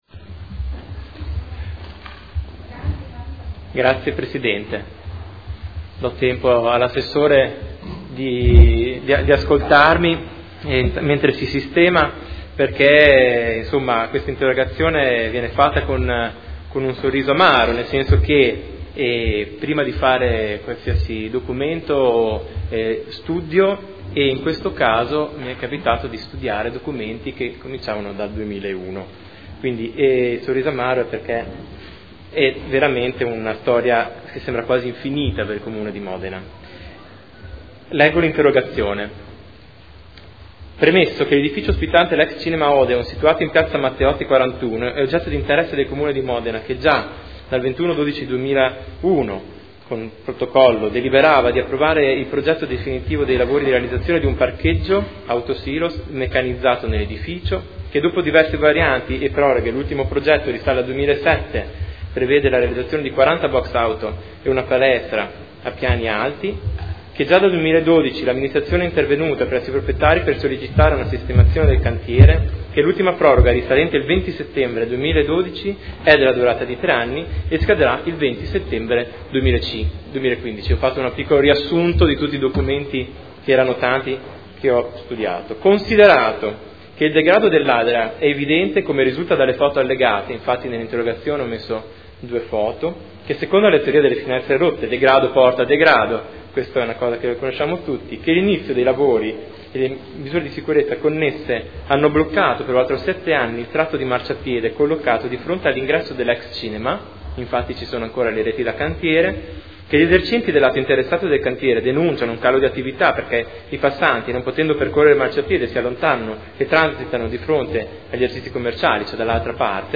Seduta del 18/06/2015. Interrogazione del gruppo consiliare Per Me Modena avente per oggetto: “Cantiere Ex-Cinema Odeon”